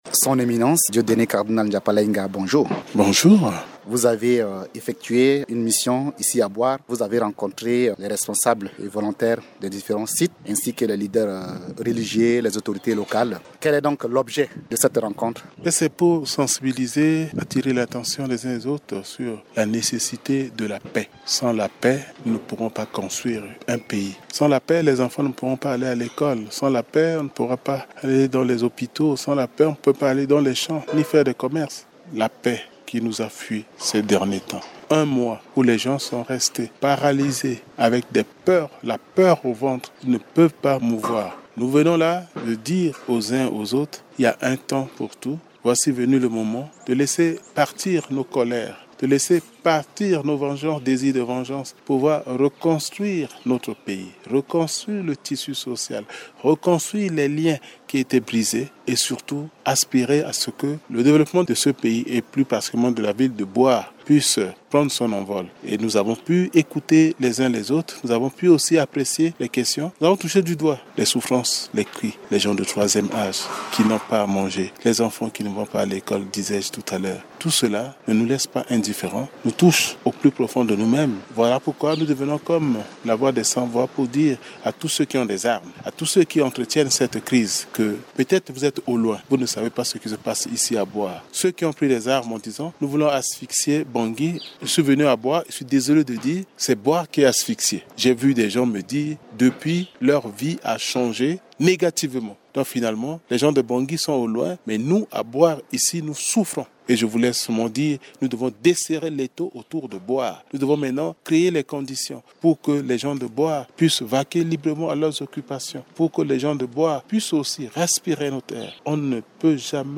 Lors d’une interview accordée à Radio Ndeke Luka, le Cardinal Dieudonné Nzapalaïnga brosse la situation sécuritaire et humanitaire des déplacés internes, singulièrement, ceux de Bouar. Il affirme, en marge d’une mission de la plateforme des confessions religieuses dans cette ville, qu’il est temps de mettre un terme à la prise en otage des pauvres populations et que ceux qui ont pris les armes doivent écouter la voix de la raison.